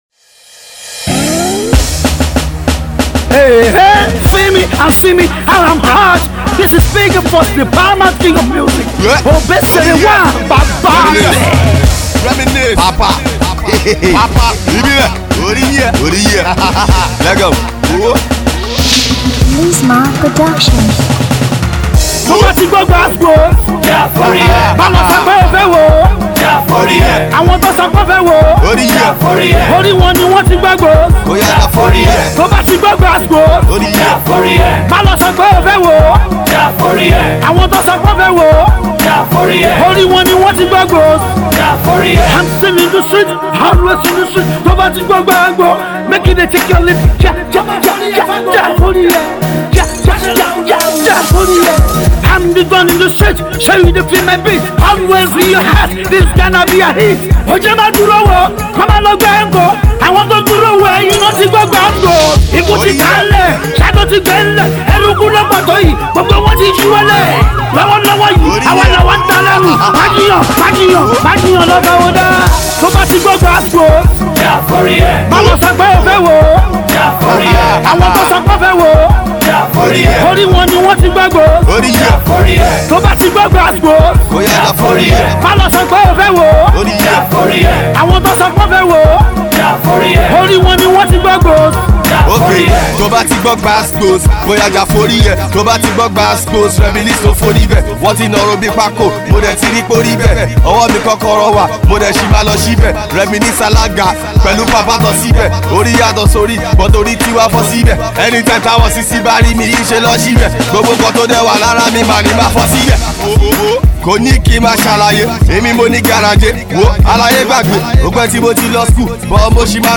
he delivers a nice rap